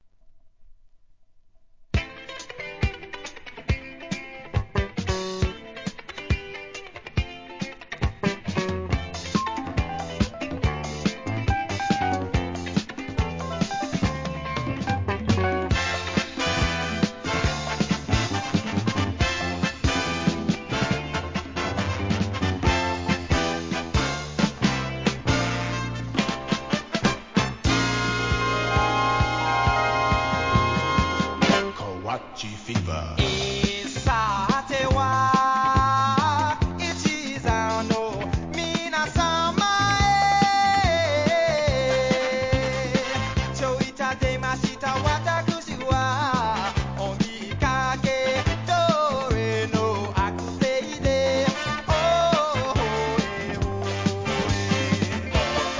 SOUL/FUNK/etc... 店舗 ただいま品切れ中です お気に入りに追加 外国人が歌う歌謡ヒットメドレー!